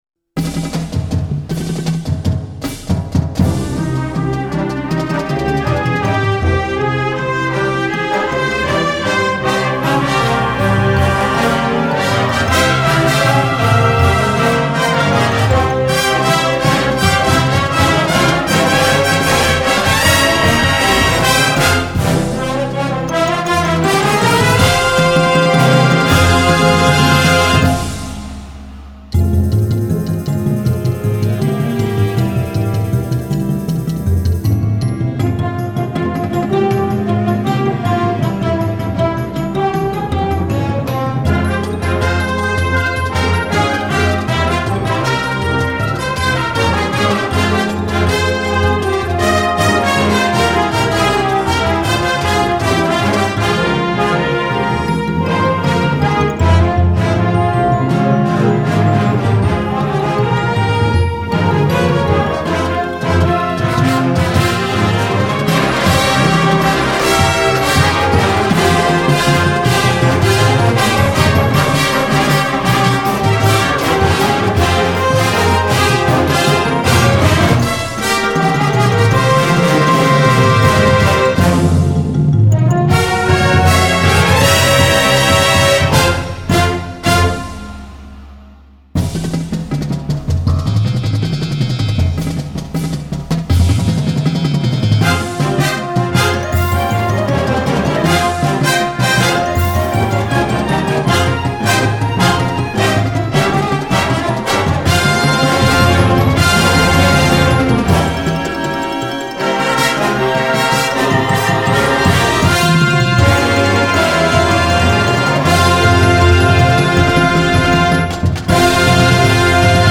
jazz themed Marching Band Show